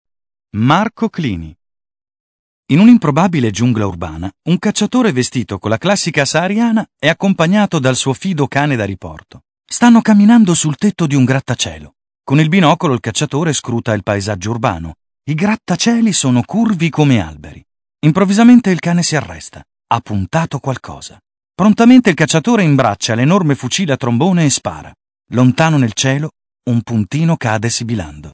Demo Audio Pubblicità Voiceover
DEMO ADAP 2000 - LETTURA